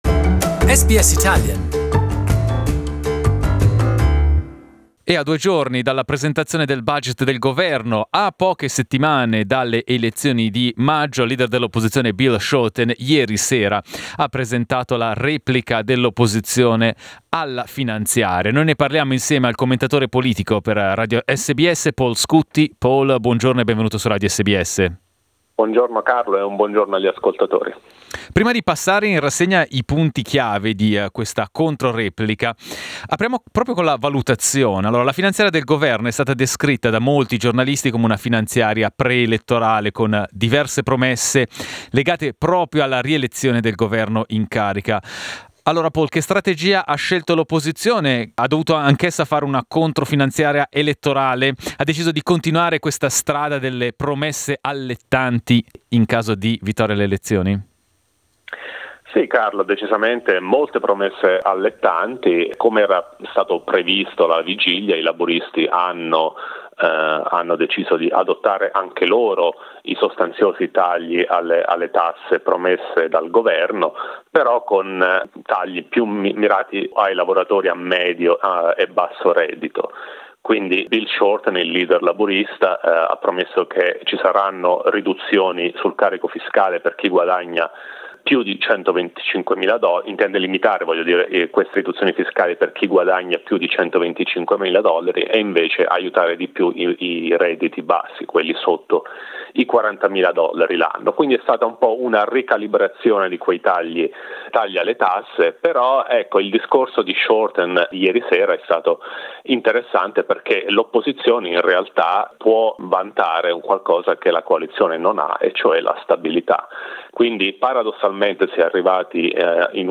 Political correspondent